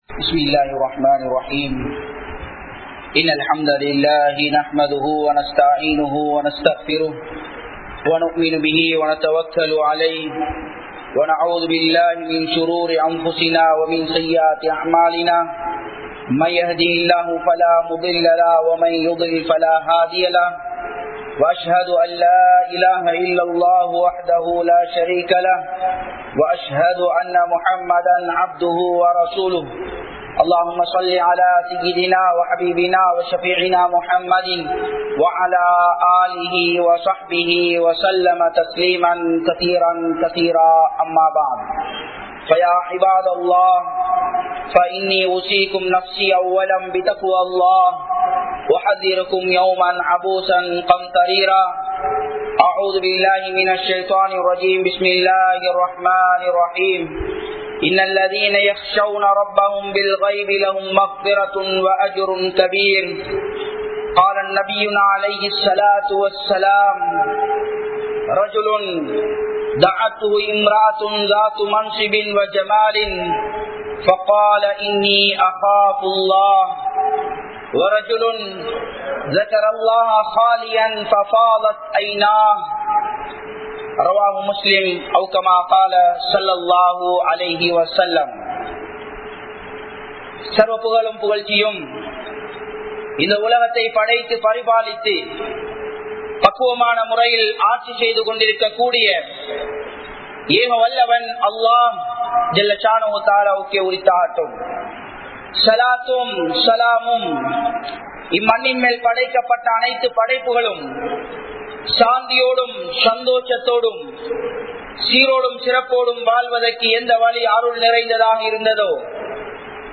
Mediavinaal Etpadak Koodiya Ketta Vilaivukal(மீடியாவினால் ஏற்படக் கூடிய கெட்ட விளைவுகள்) | Audio Bayans | All Ceylon Muslim Youth Community | Addalaichenai
Thambala Muhideen Jumua Masjith